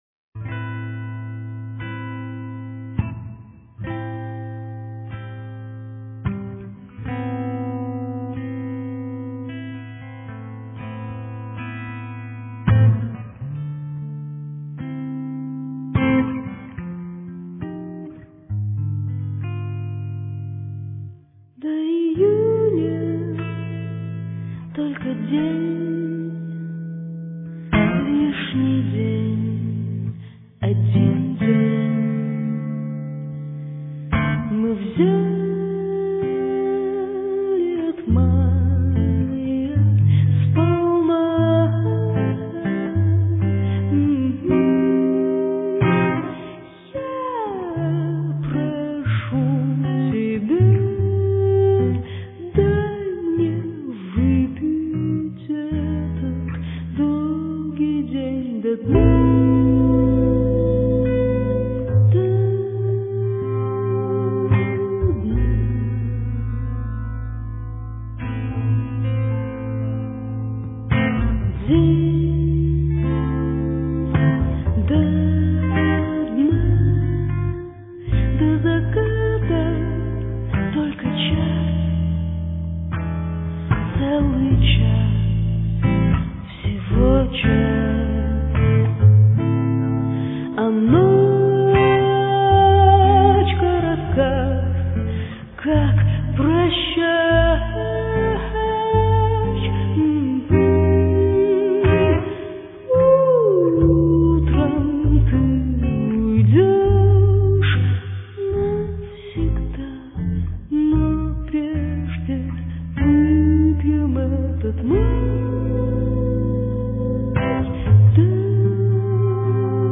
Блюз